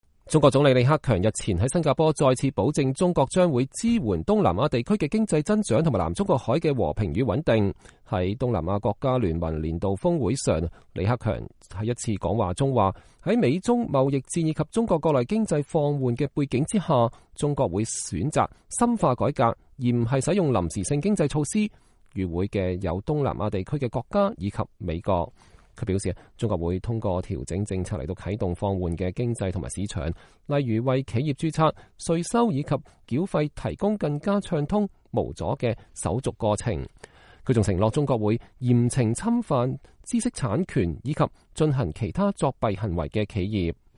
中國總理李克強在第44屆“新加坡講座”發表講話。（2018年11月13日）